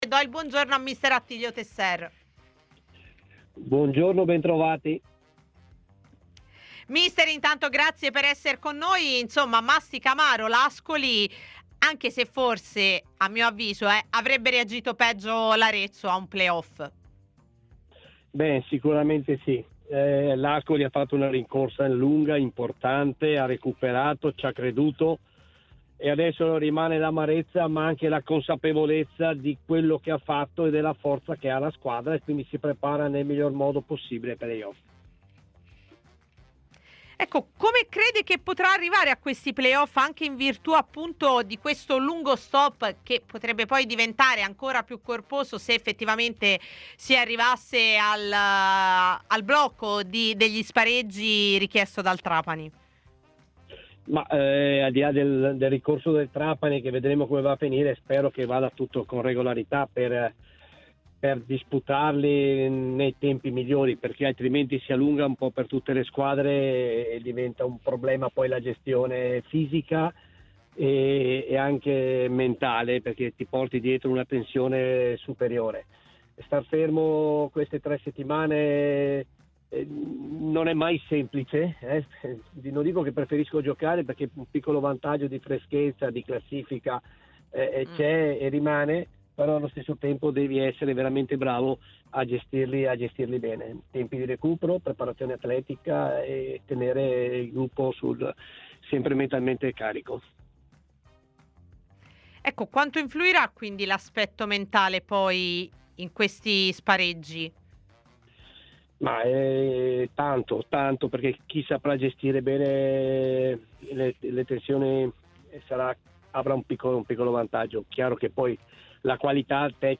Mister Attilio Tesser è intervenuto ai microfoni di TMW Radio, nel corso del programma "A Tutta C", in onda anche su Il61, per commentare la fine della regular season e le prospettive dei play-off di Serie C.